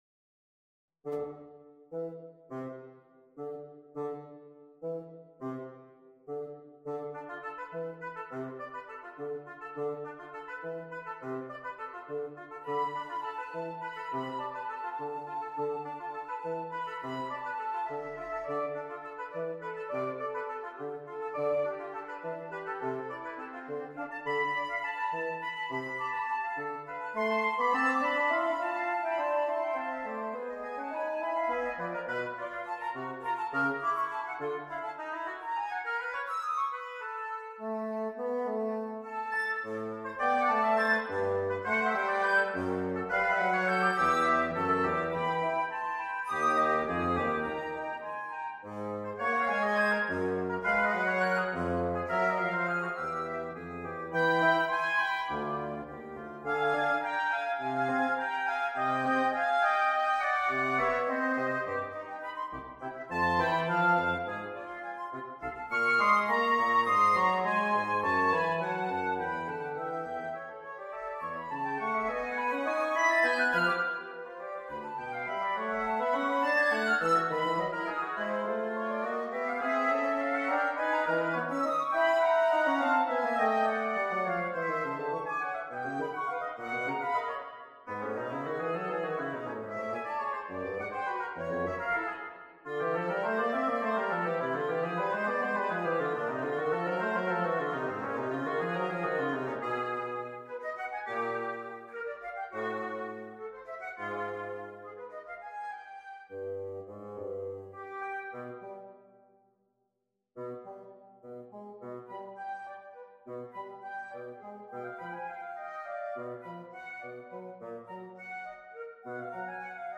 Fumage – Trio for Flute, Oboe, and Bassoon (Halloween Competition Submission)